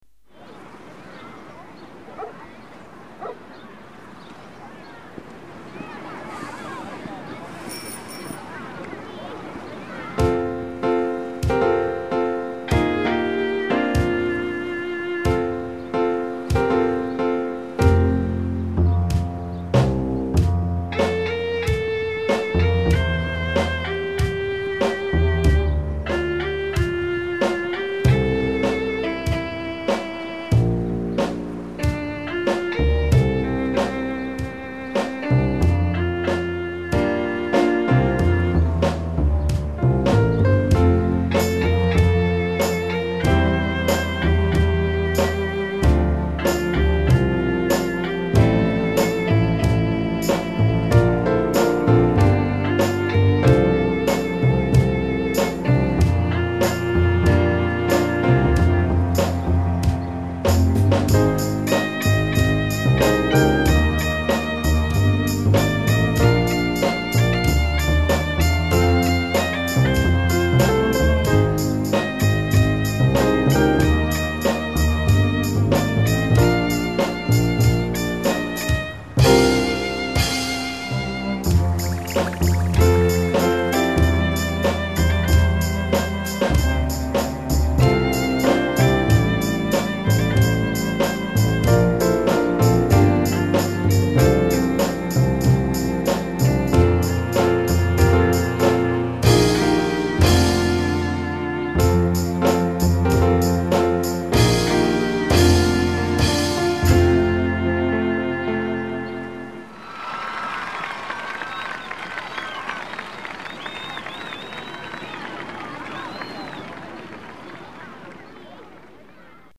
ドラムパートは、頑張ったなりのまあまあなデキになりました。